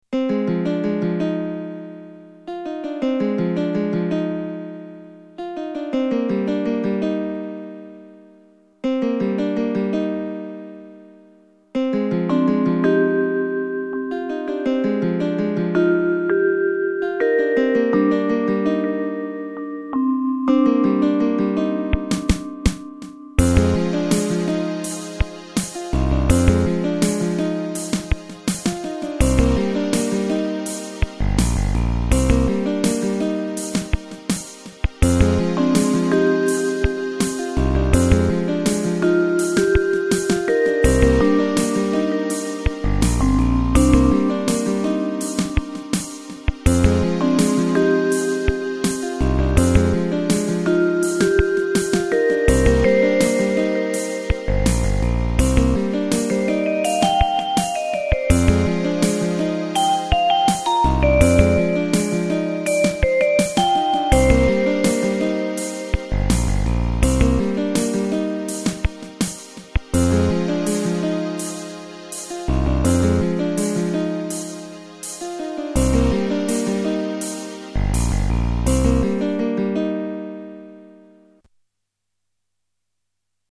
Jazzy relaxing tune, suitable for a puzzle or thinking game.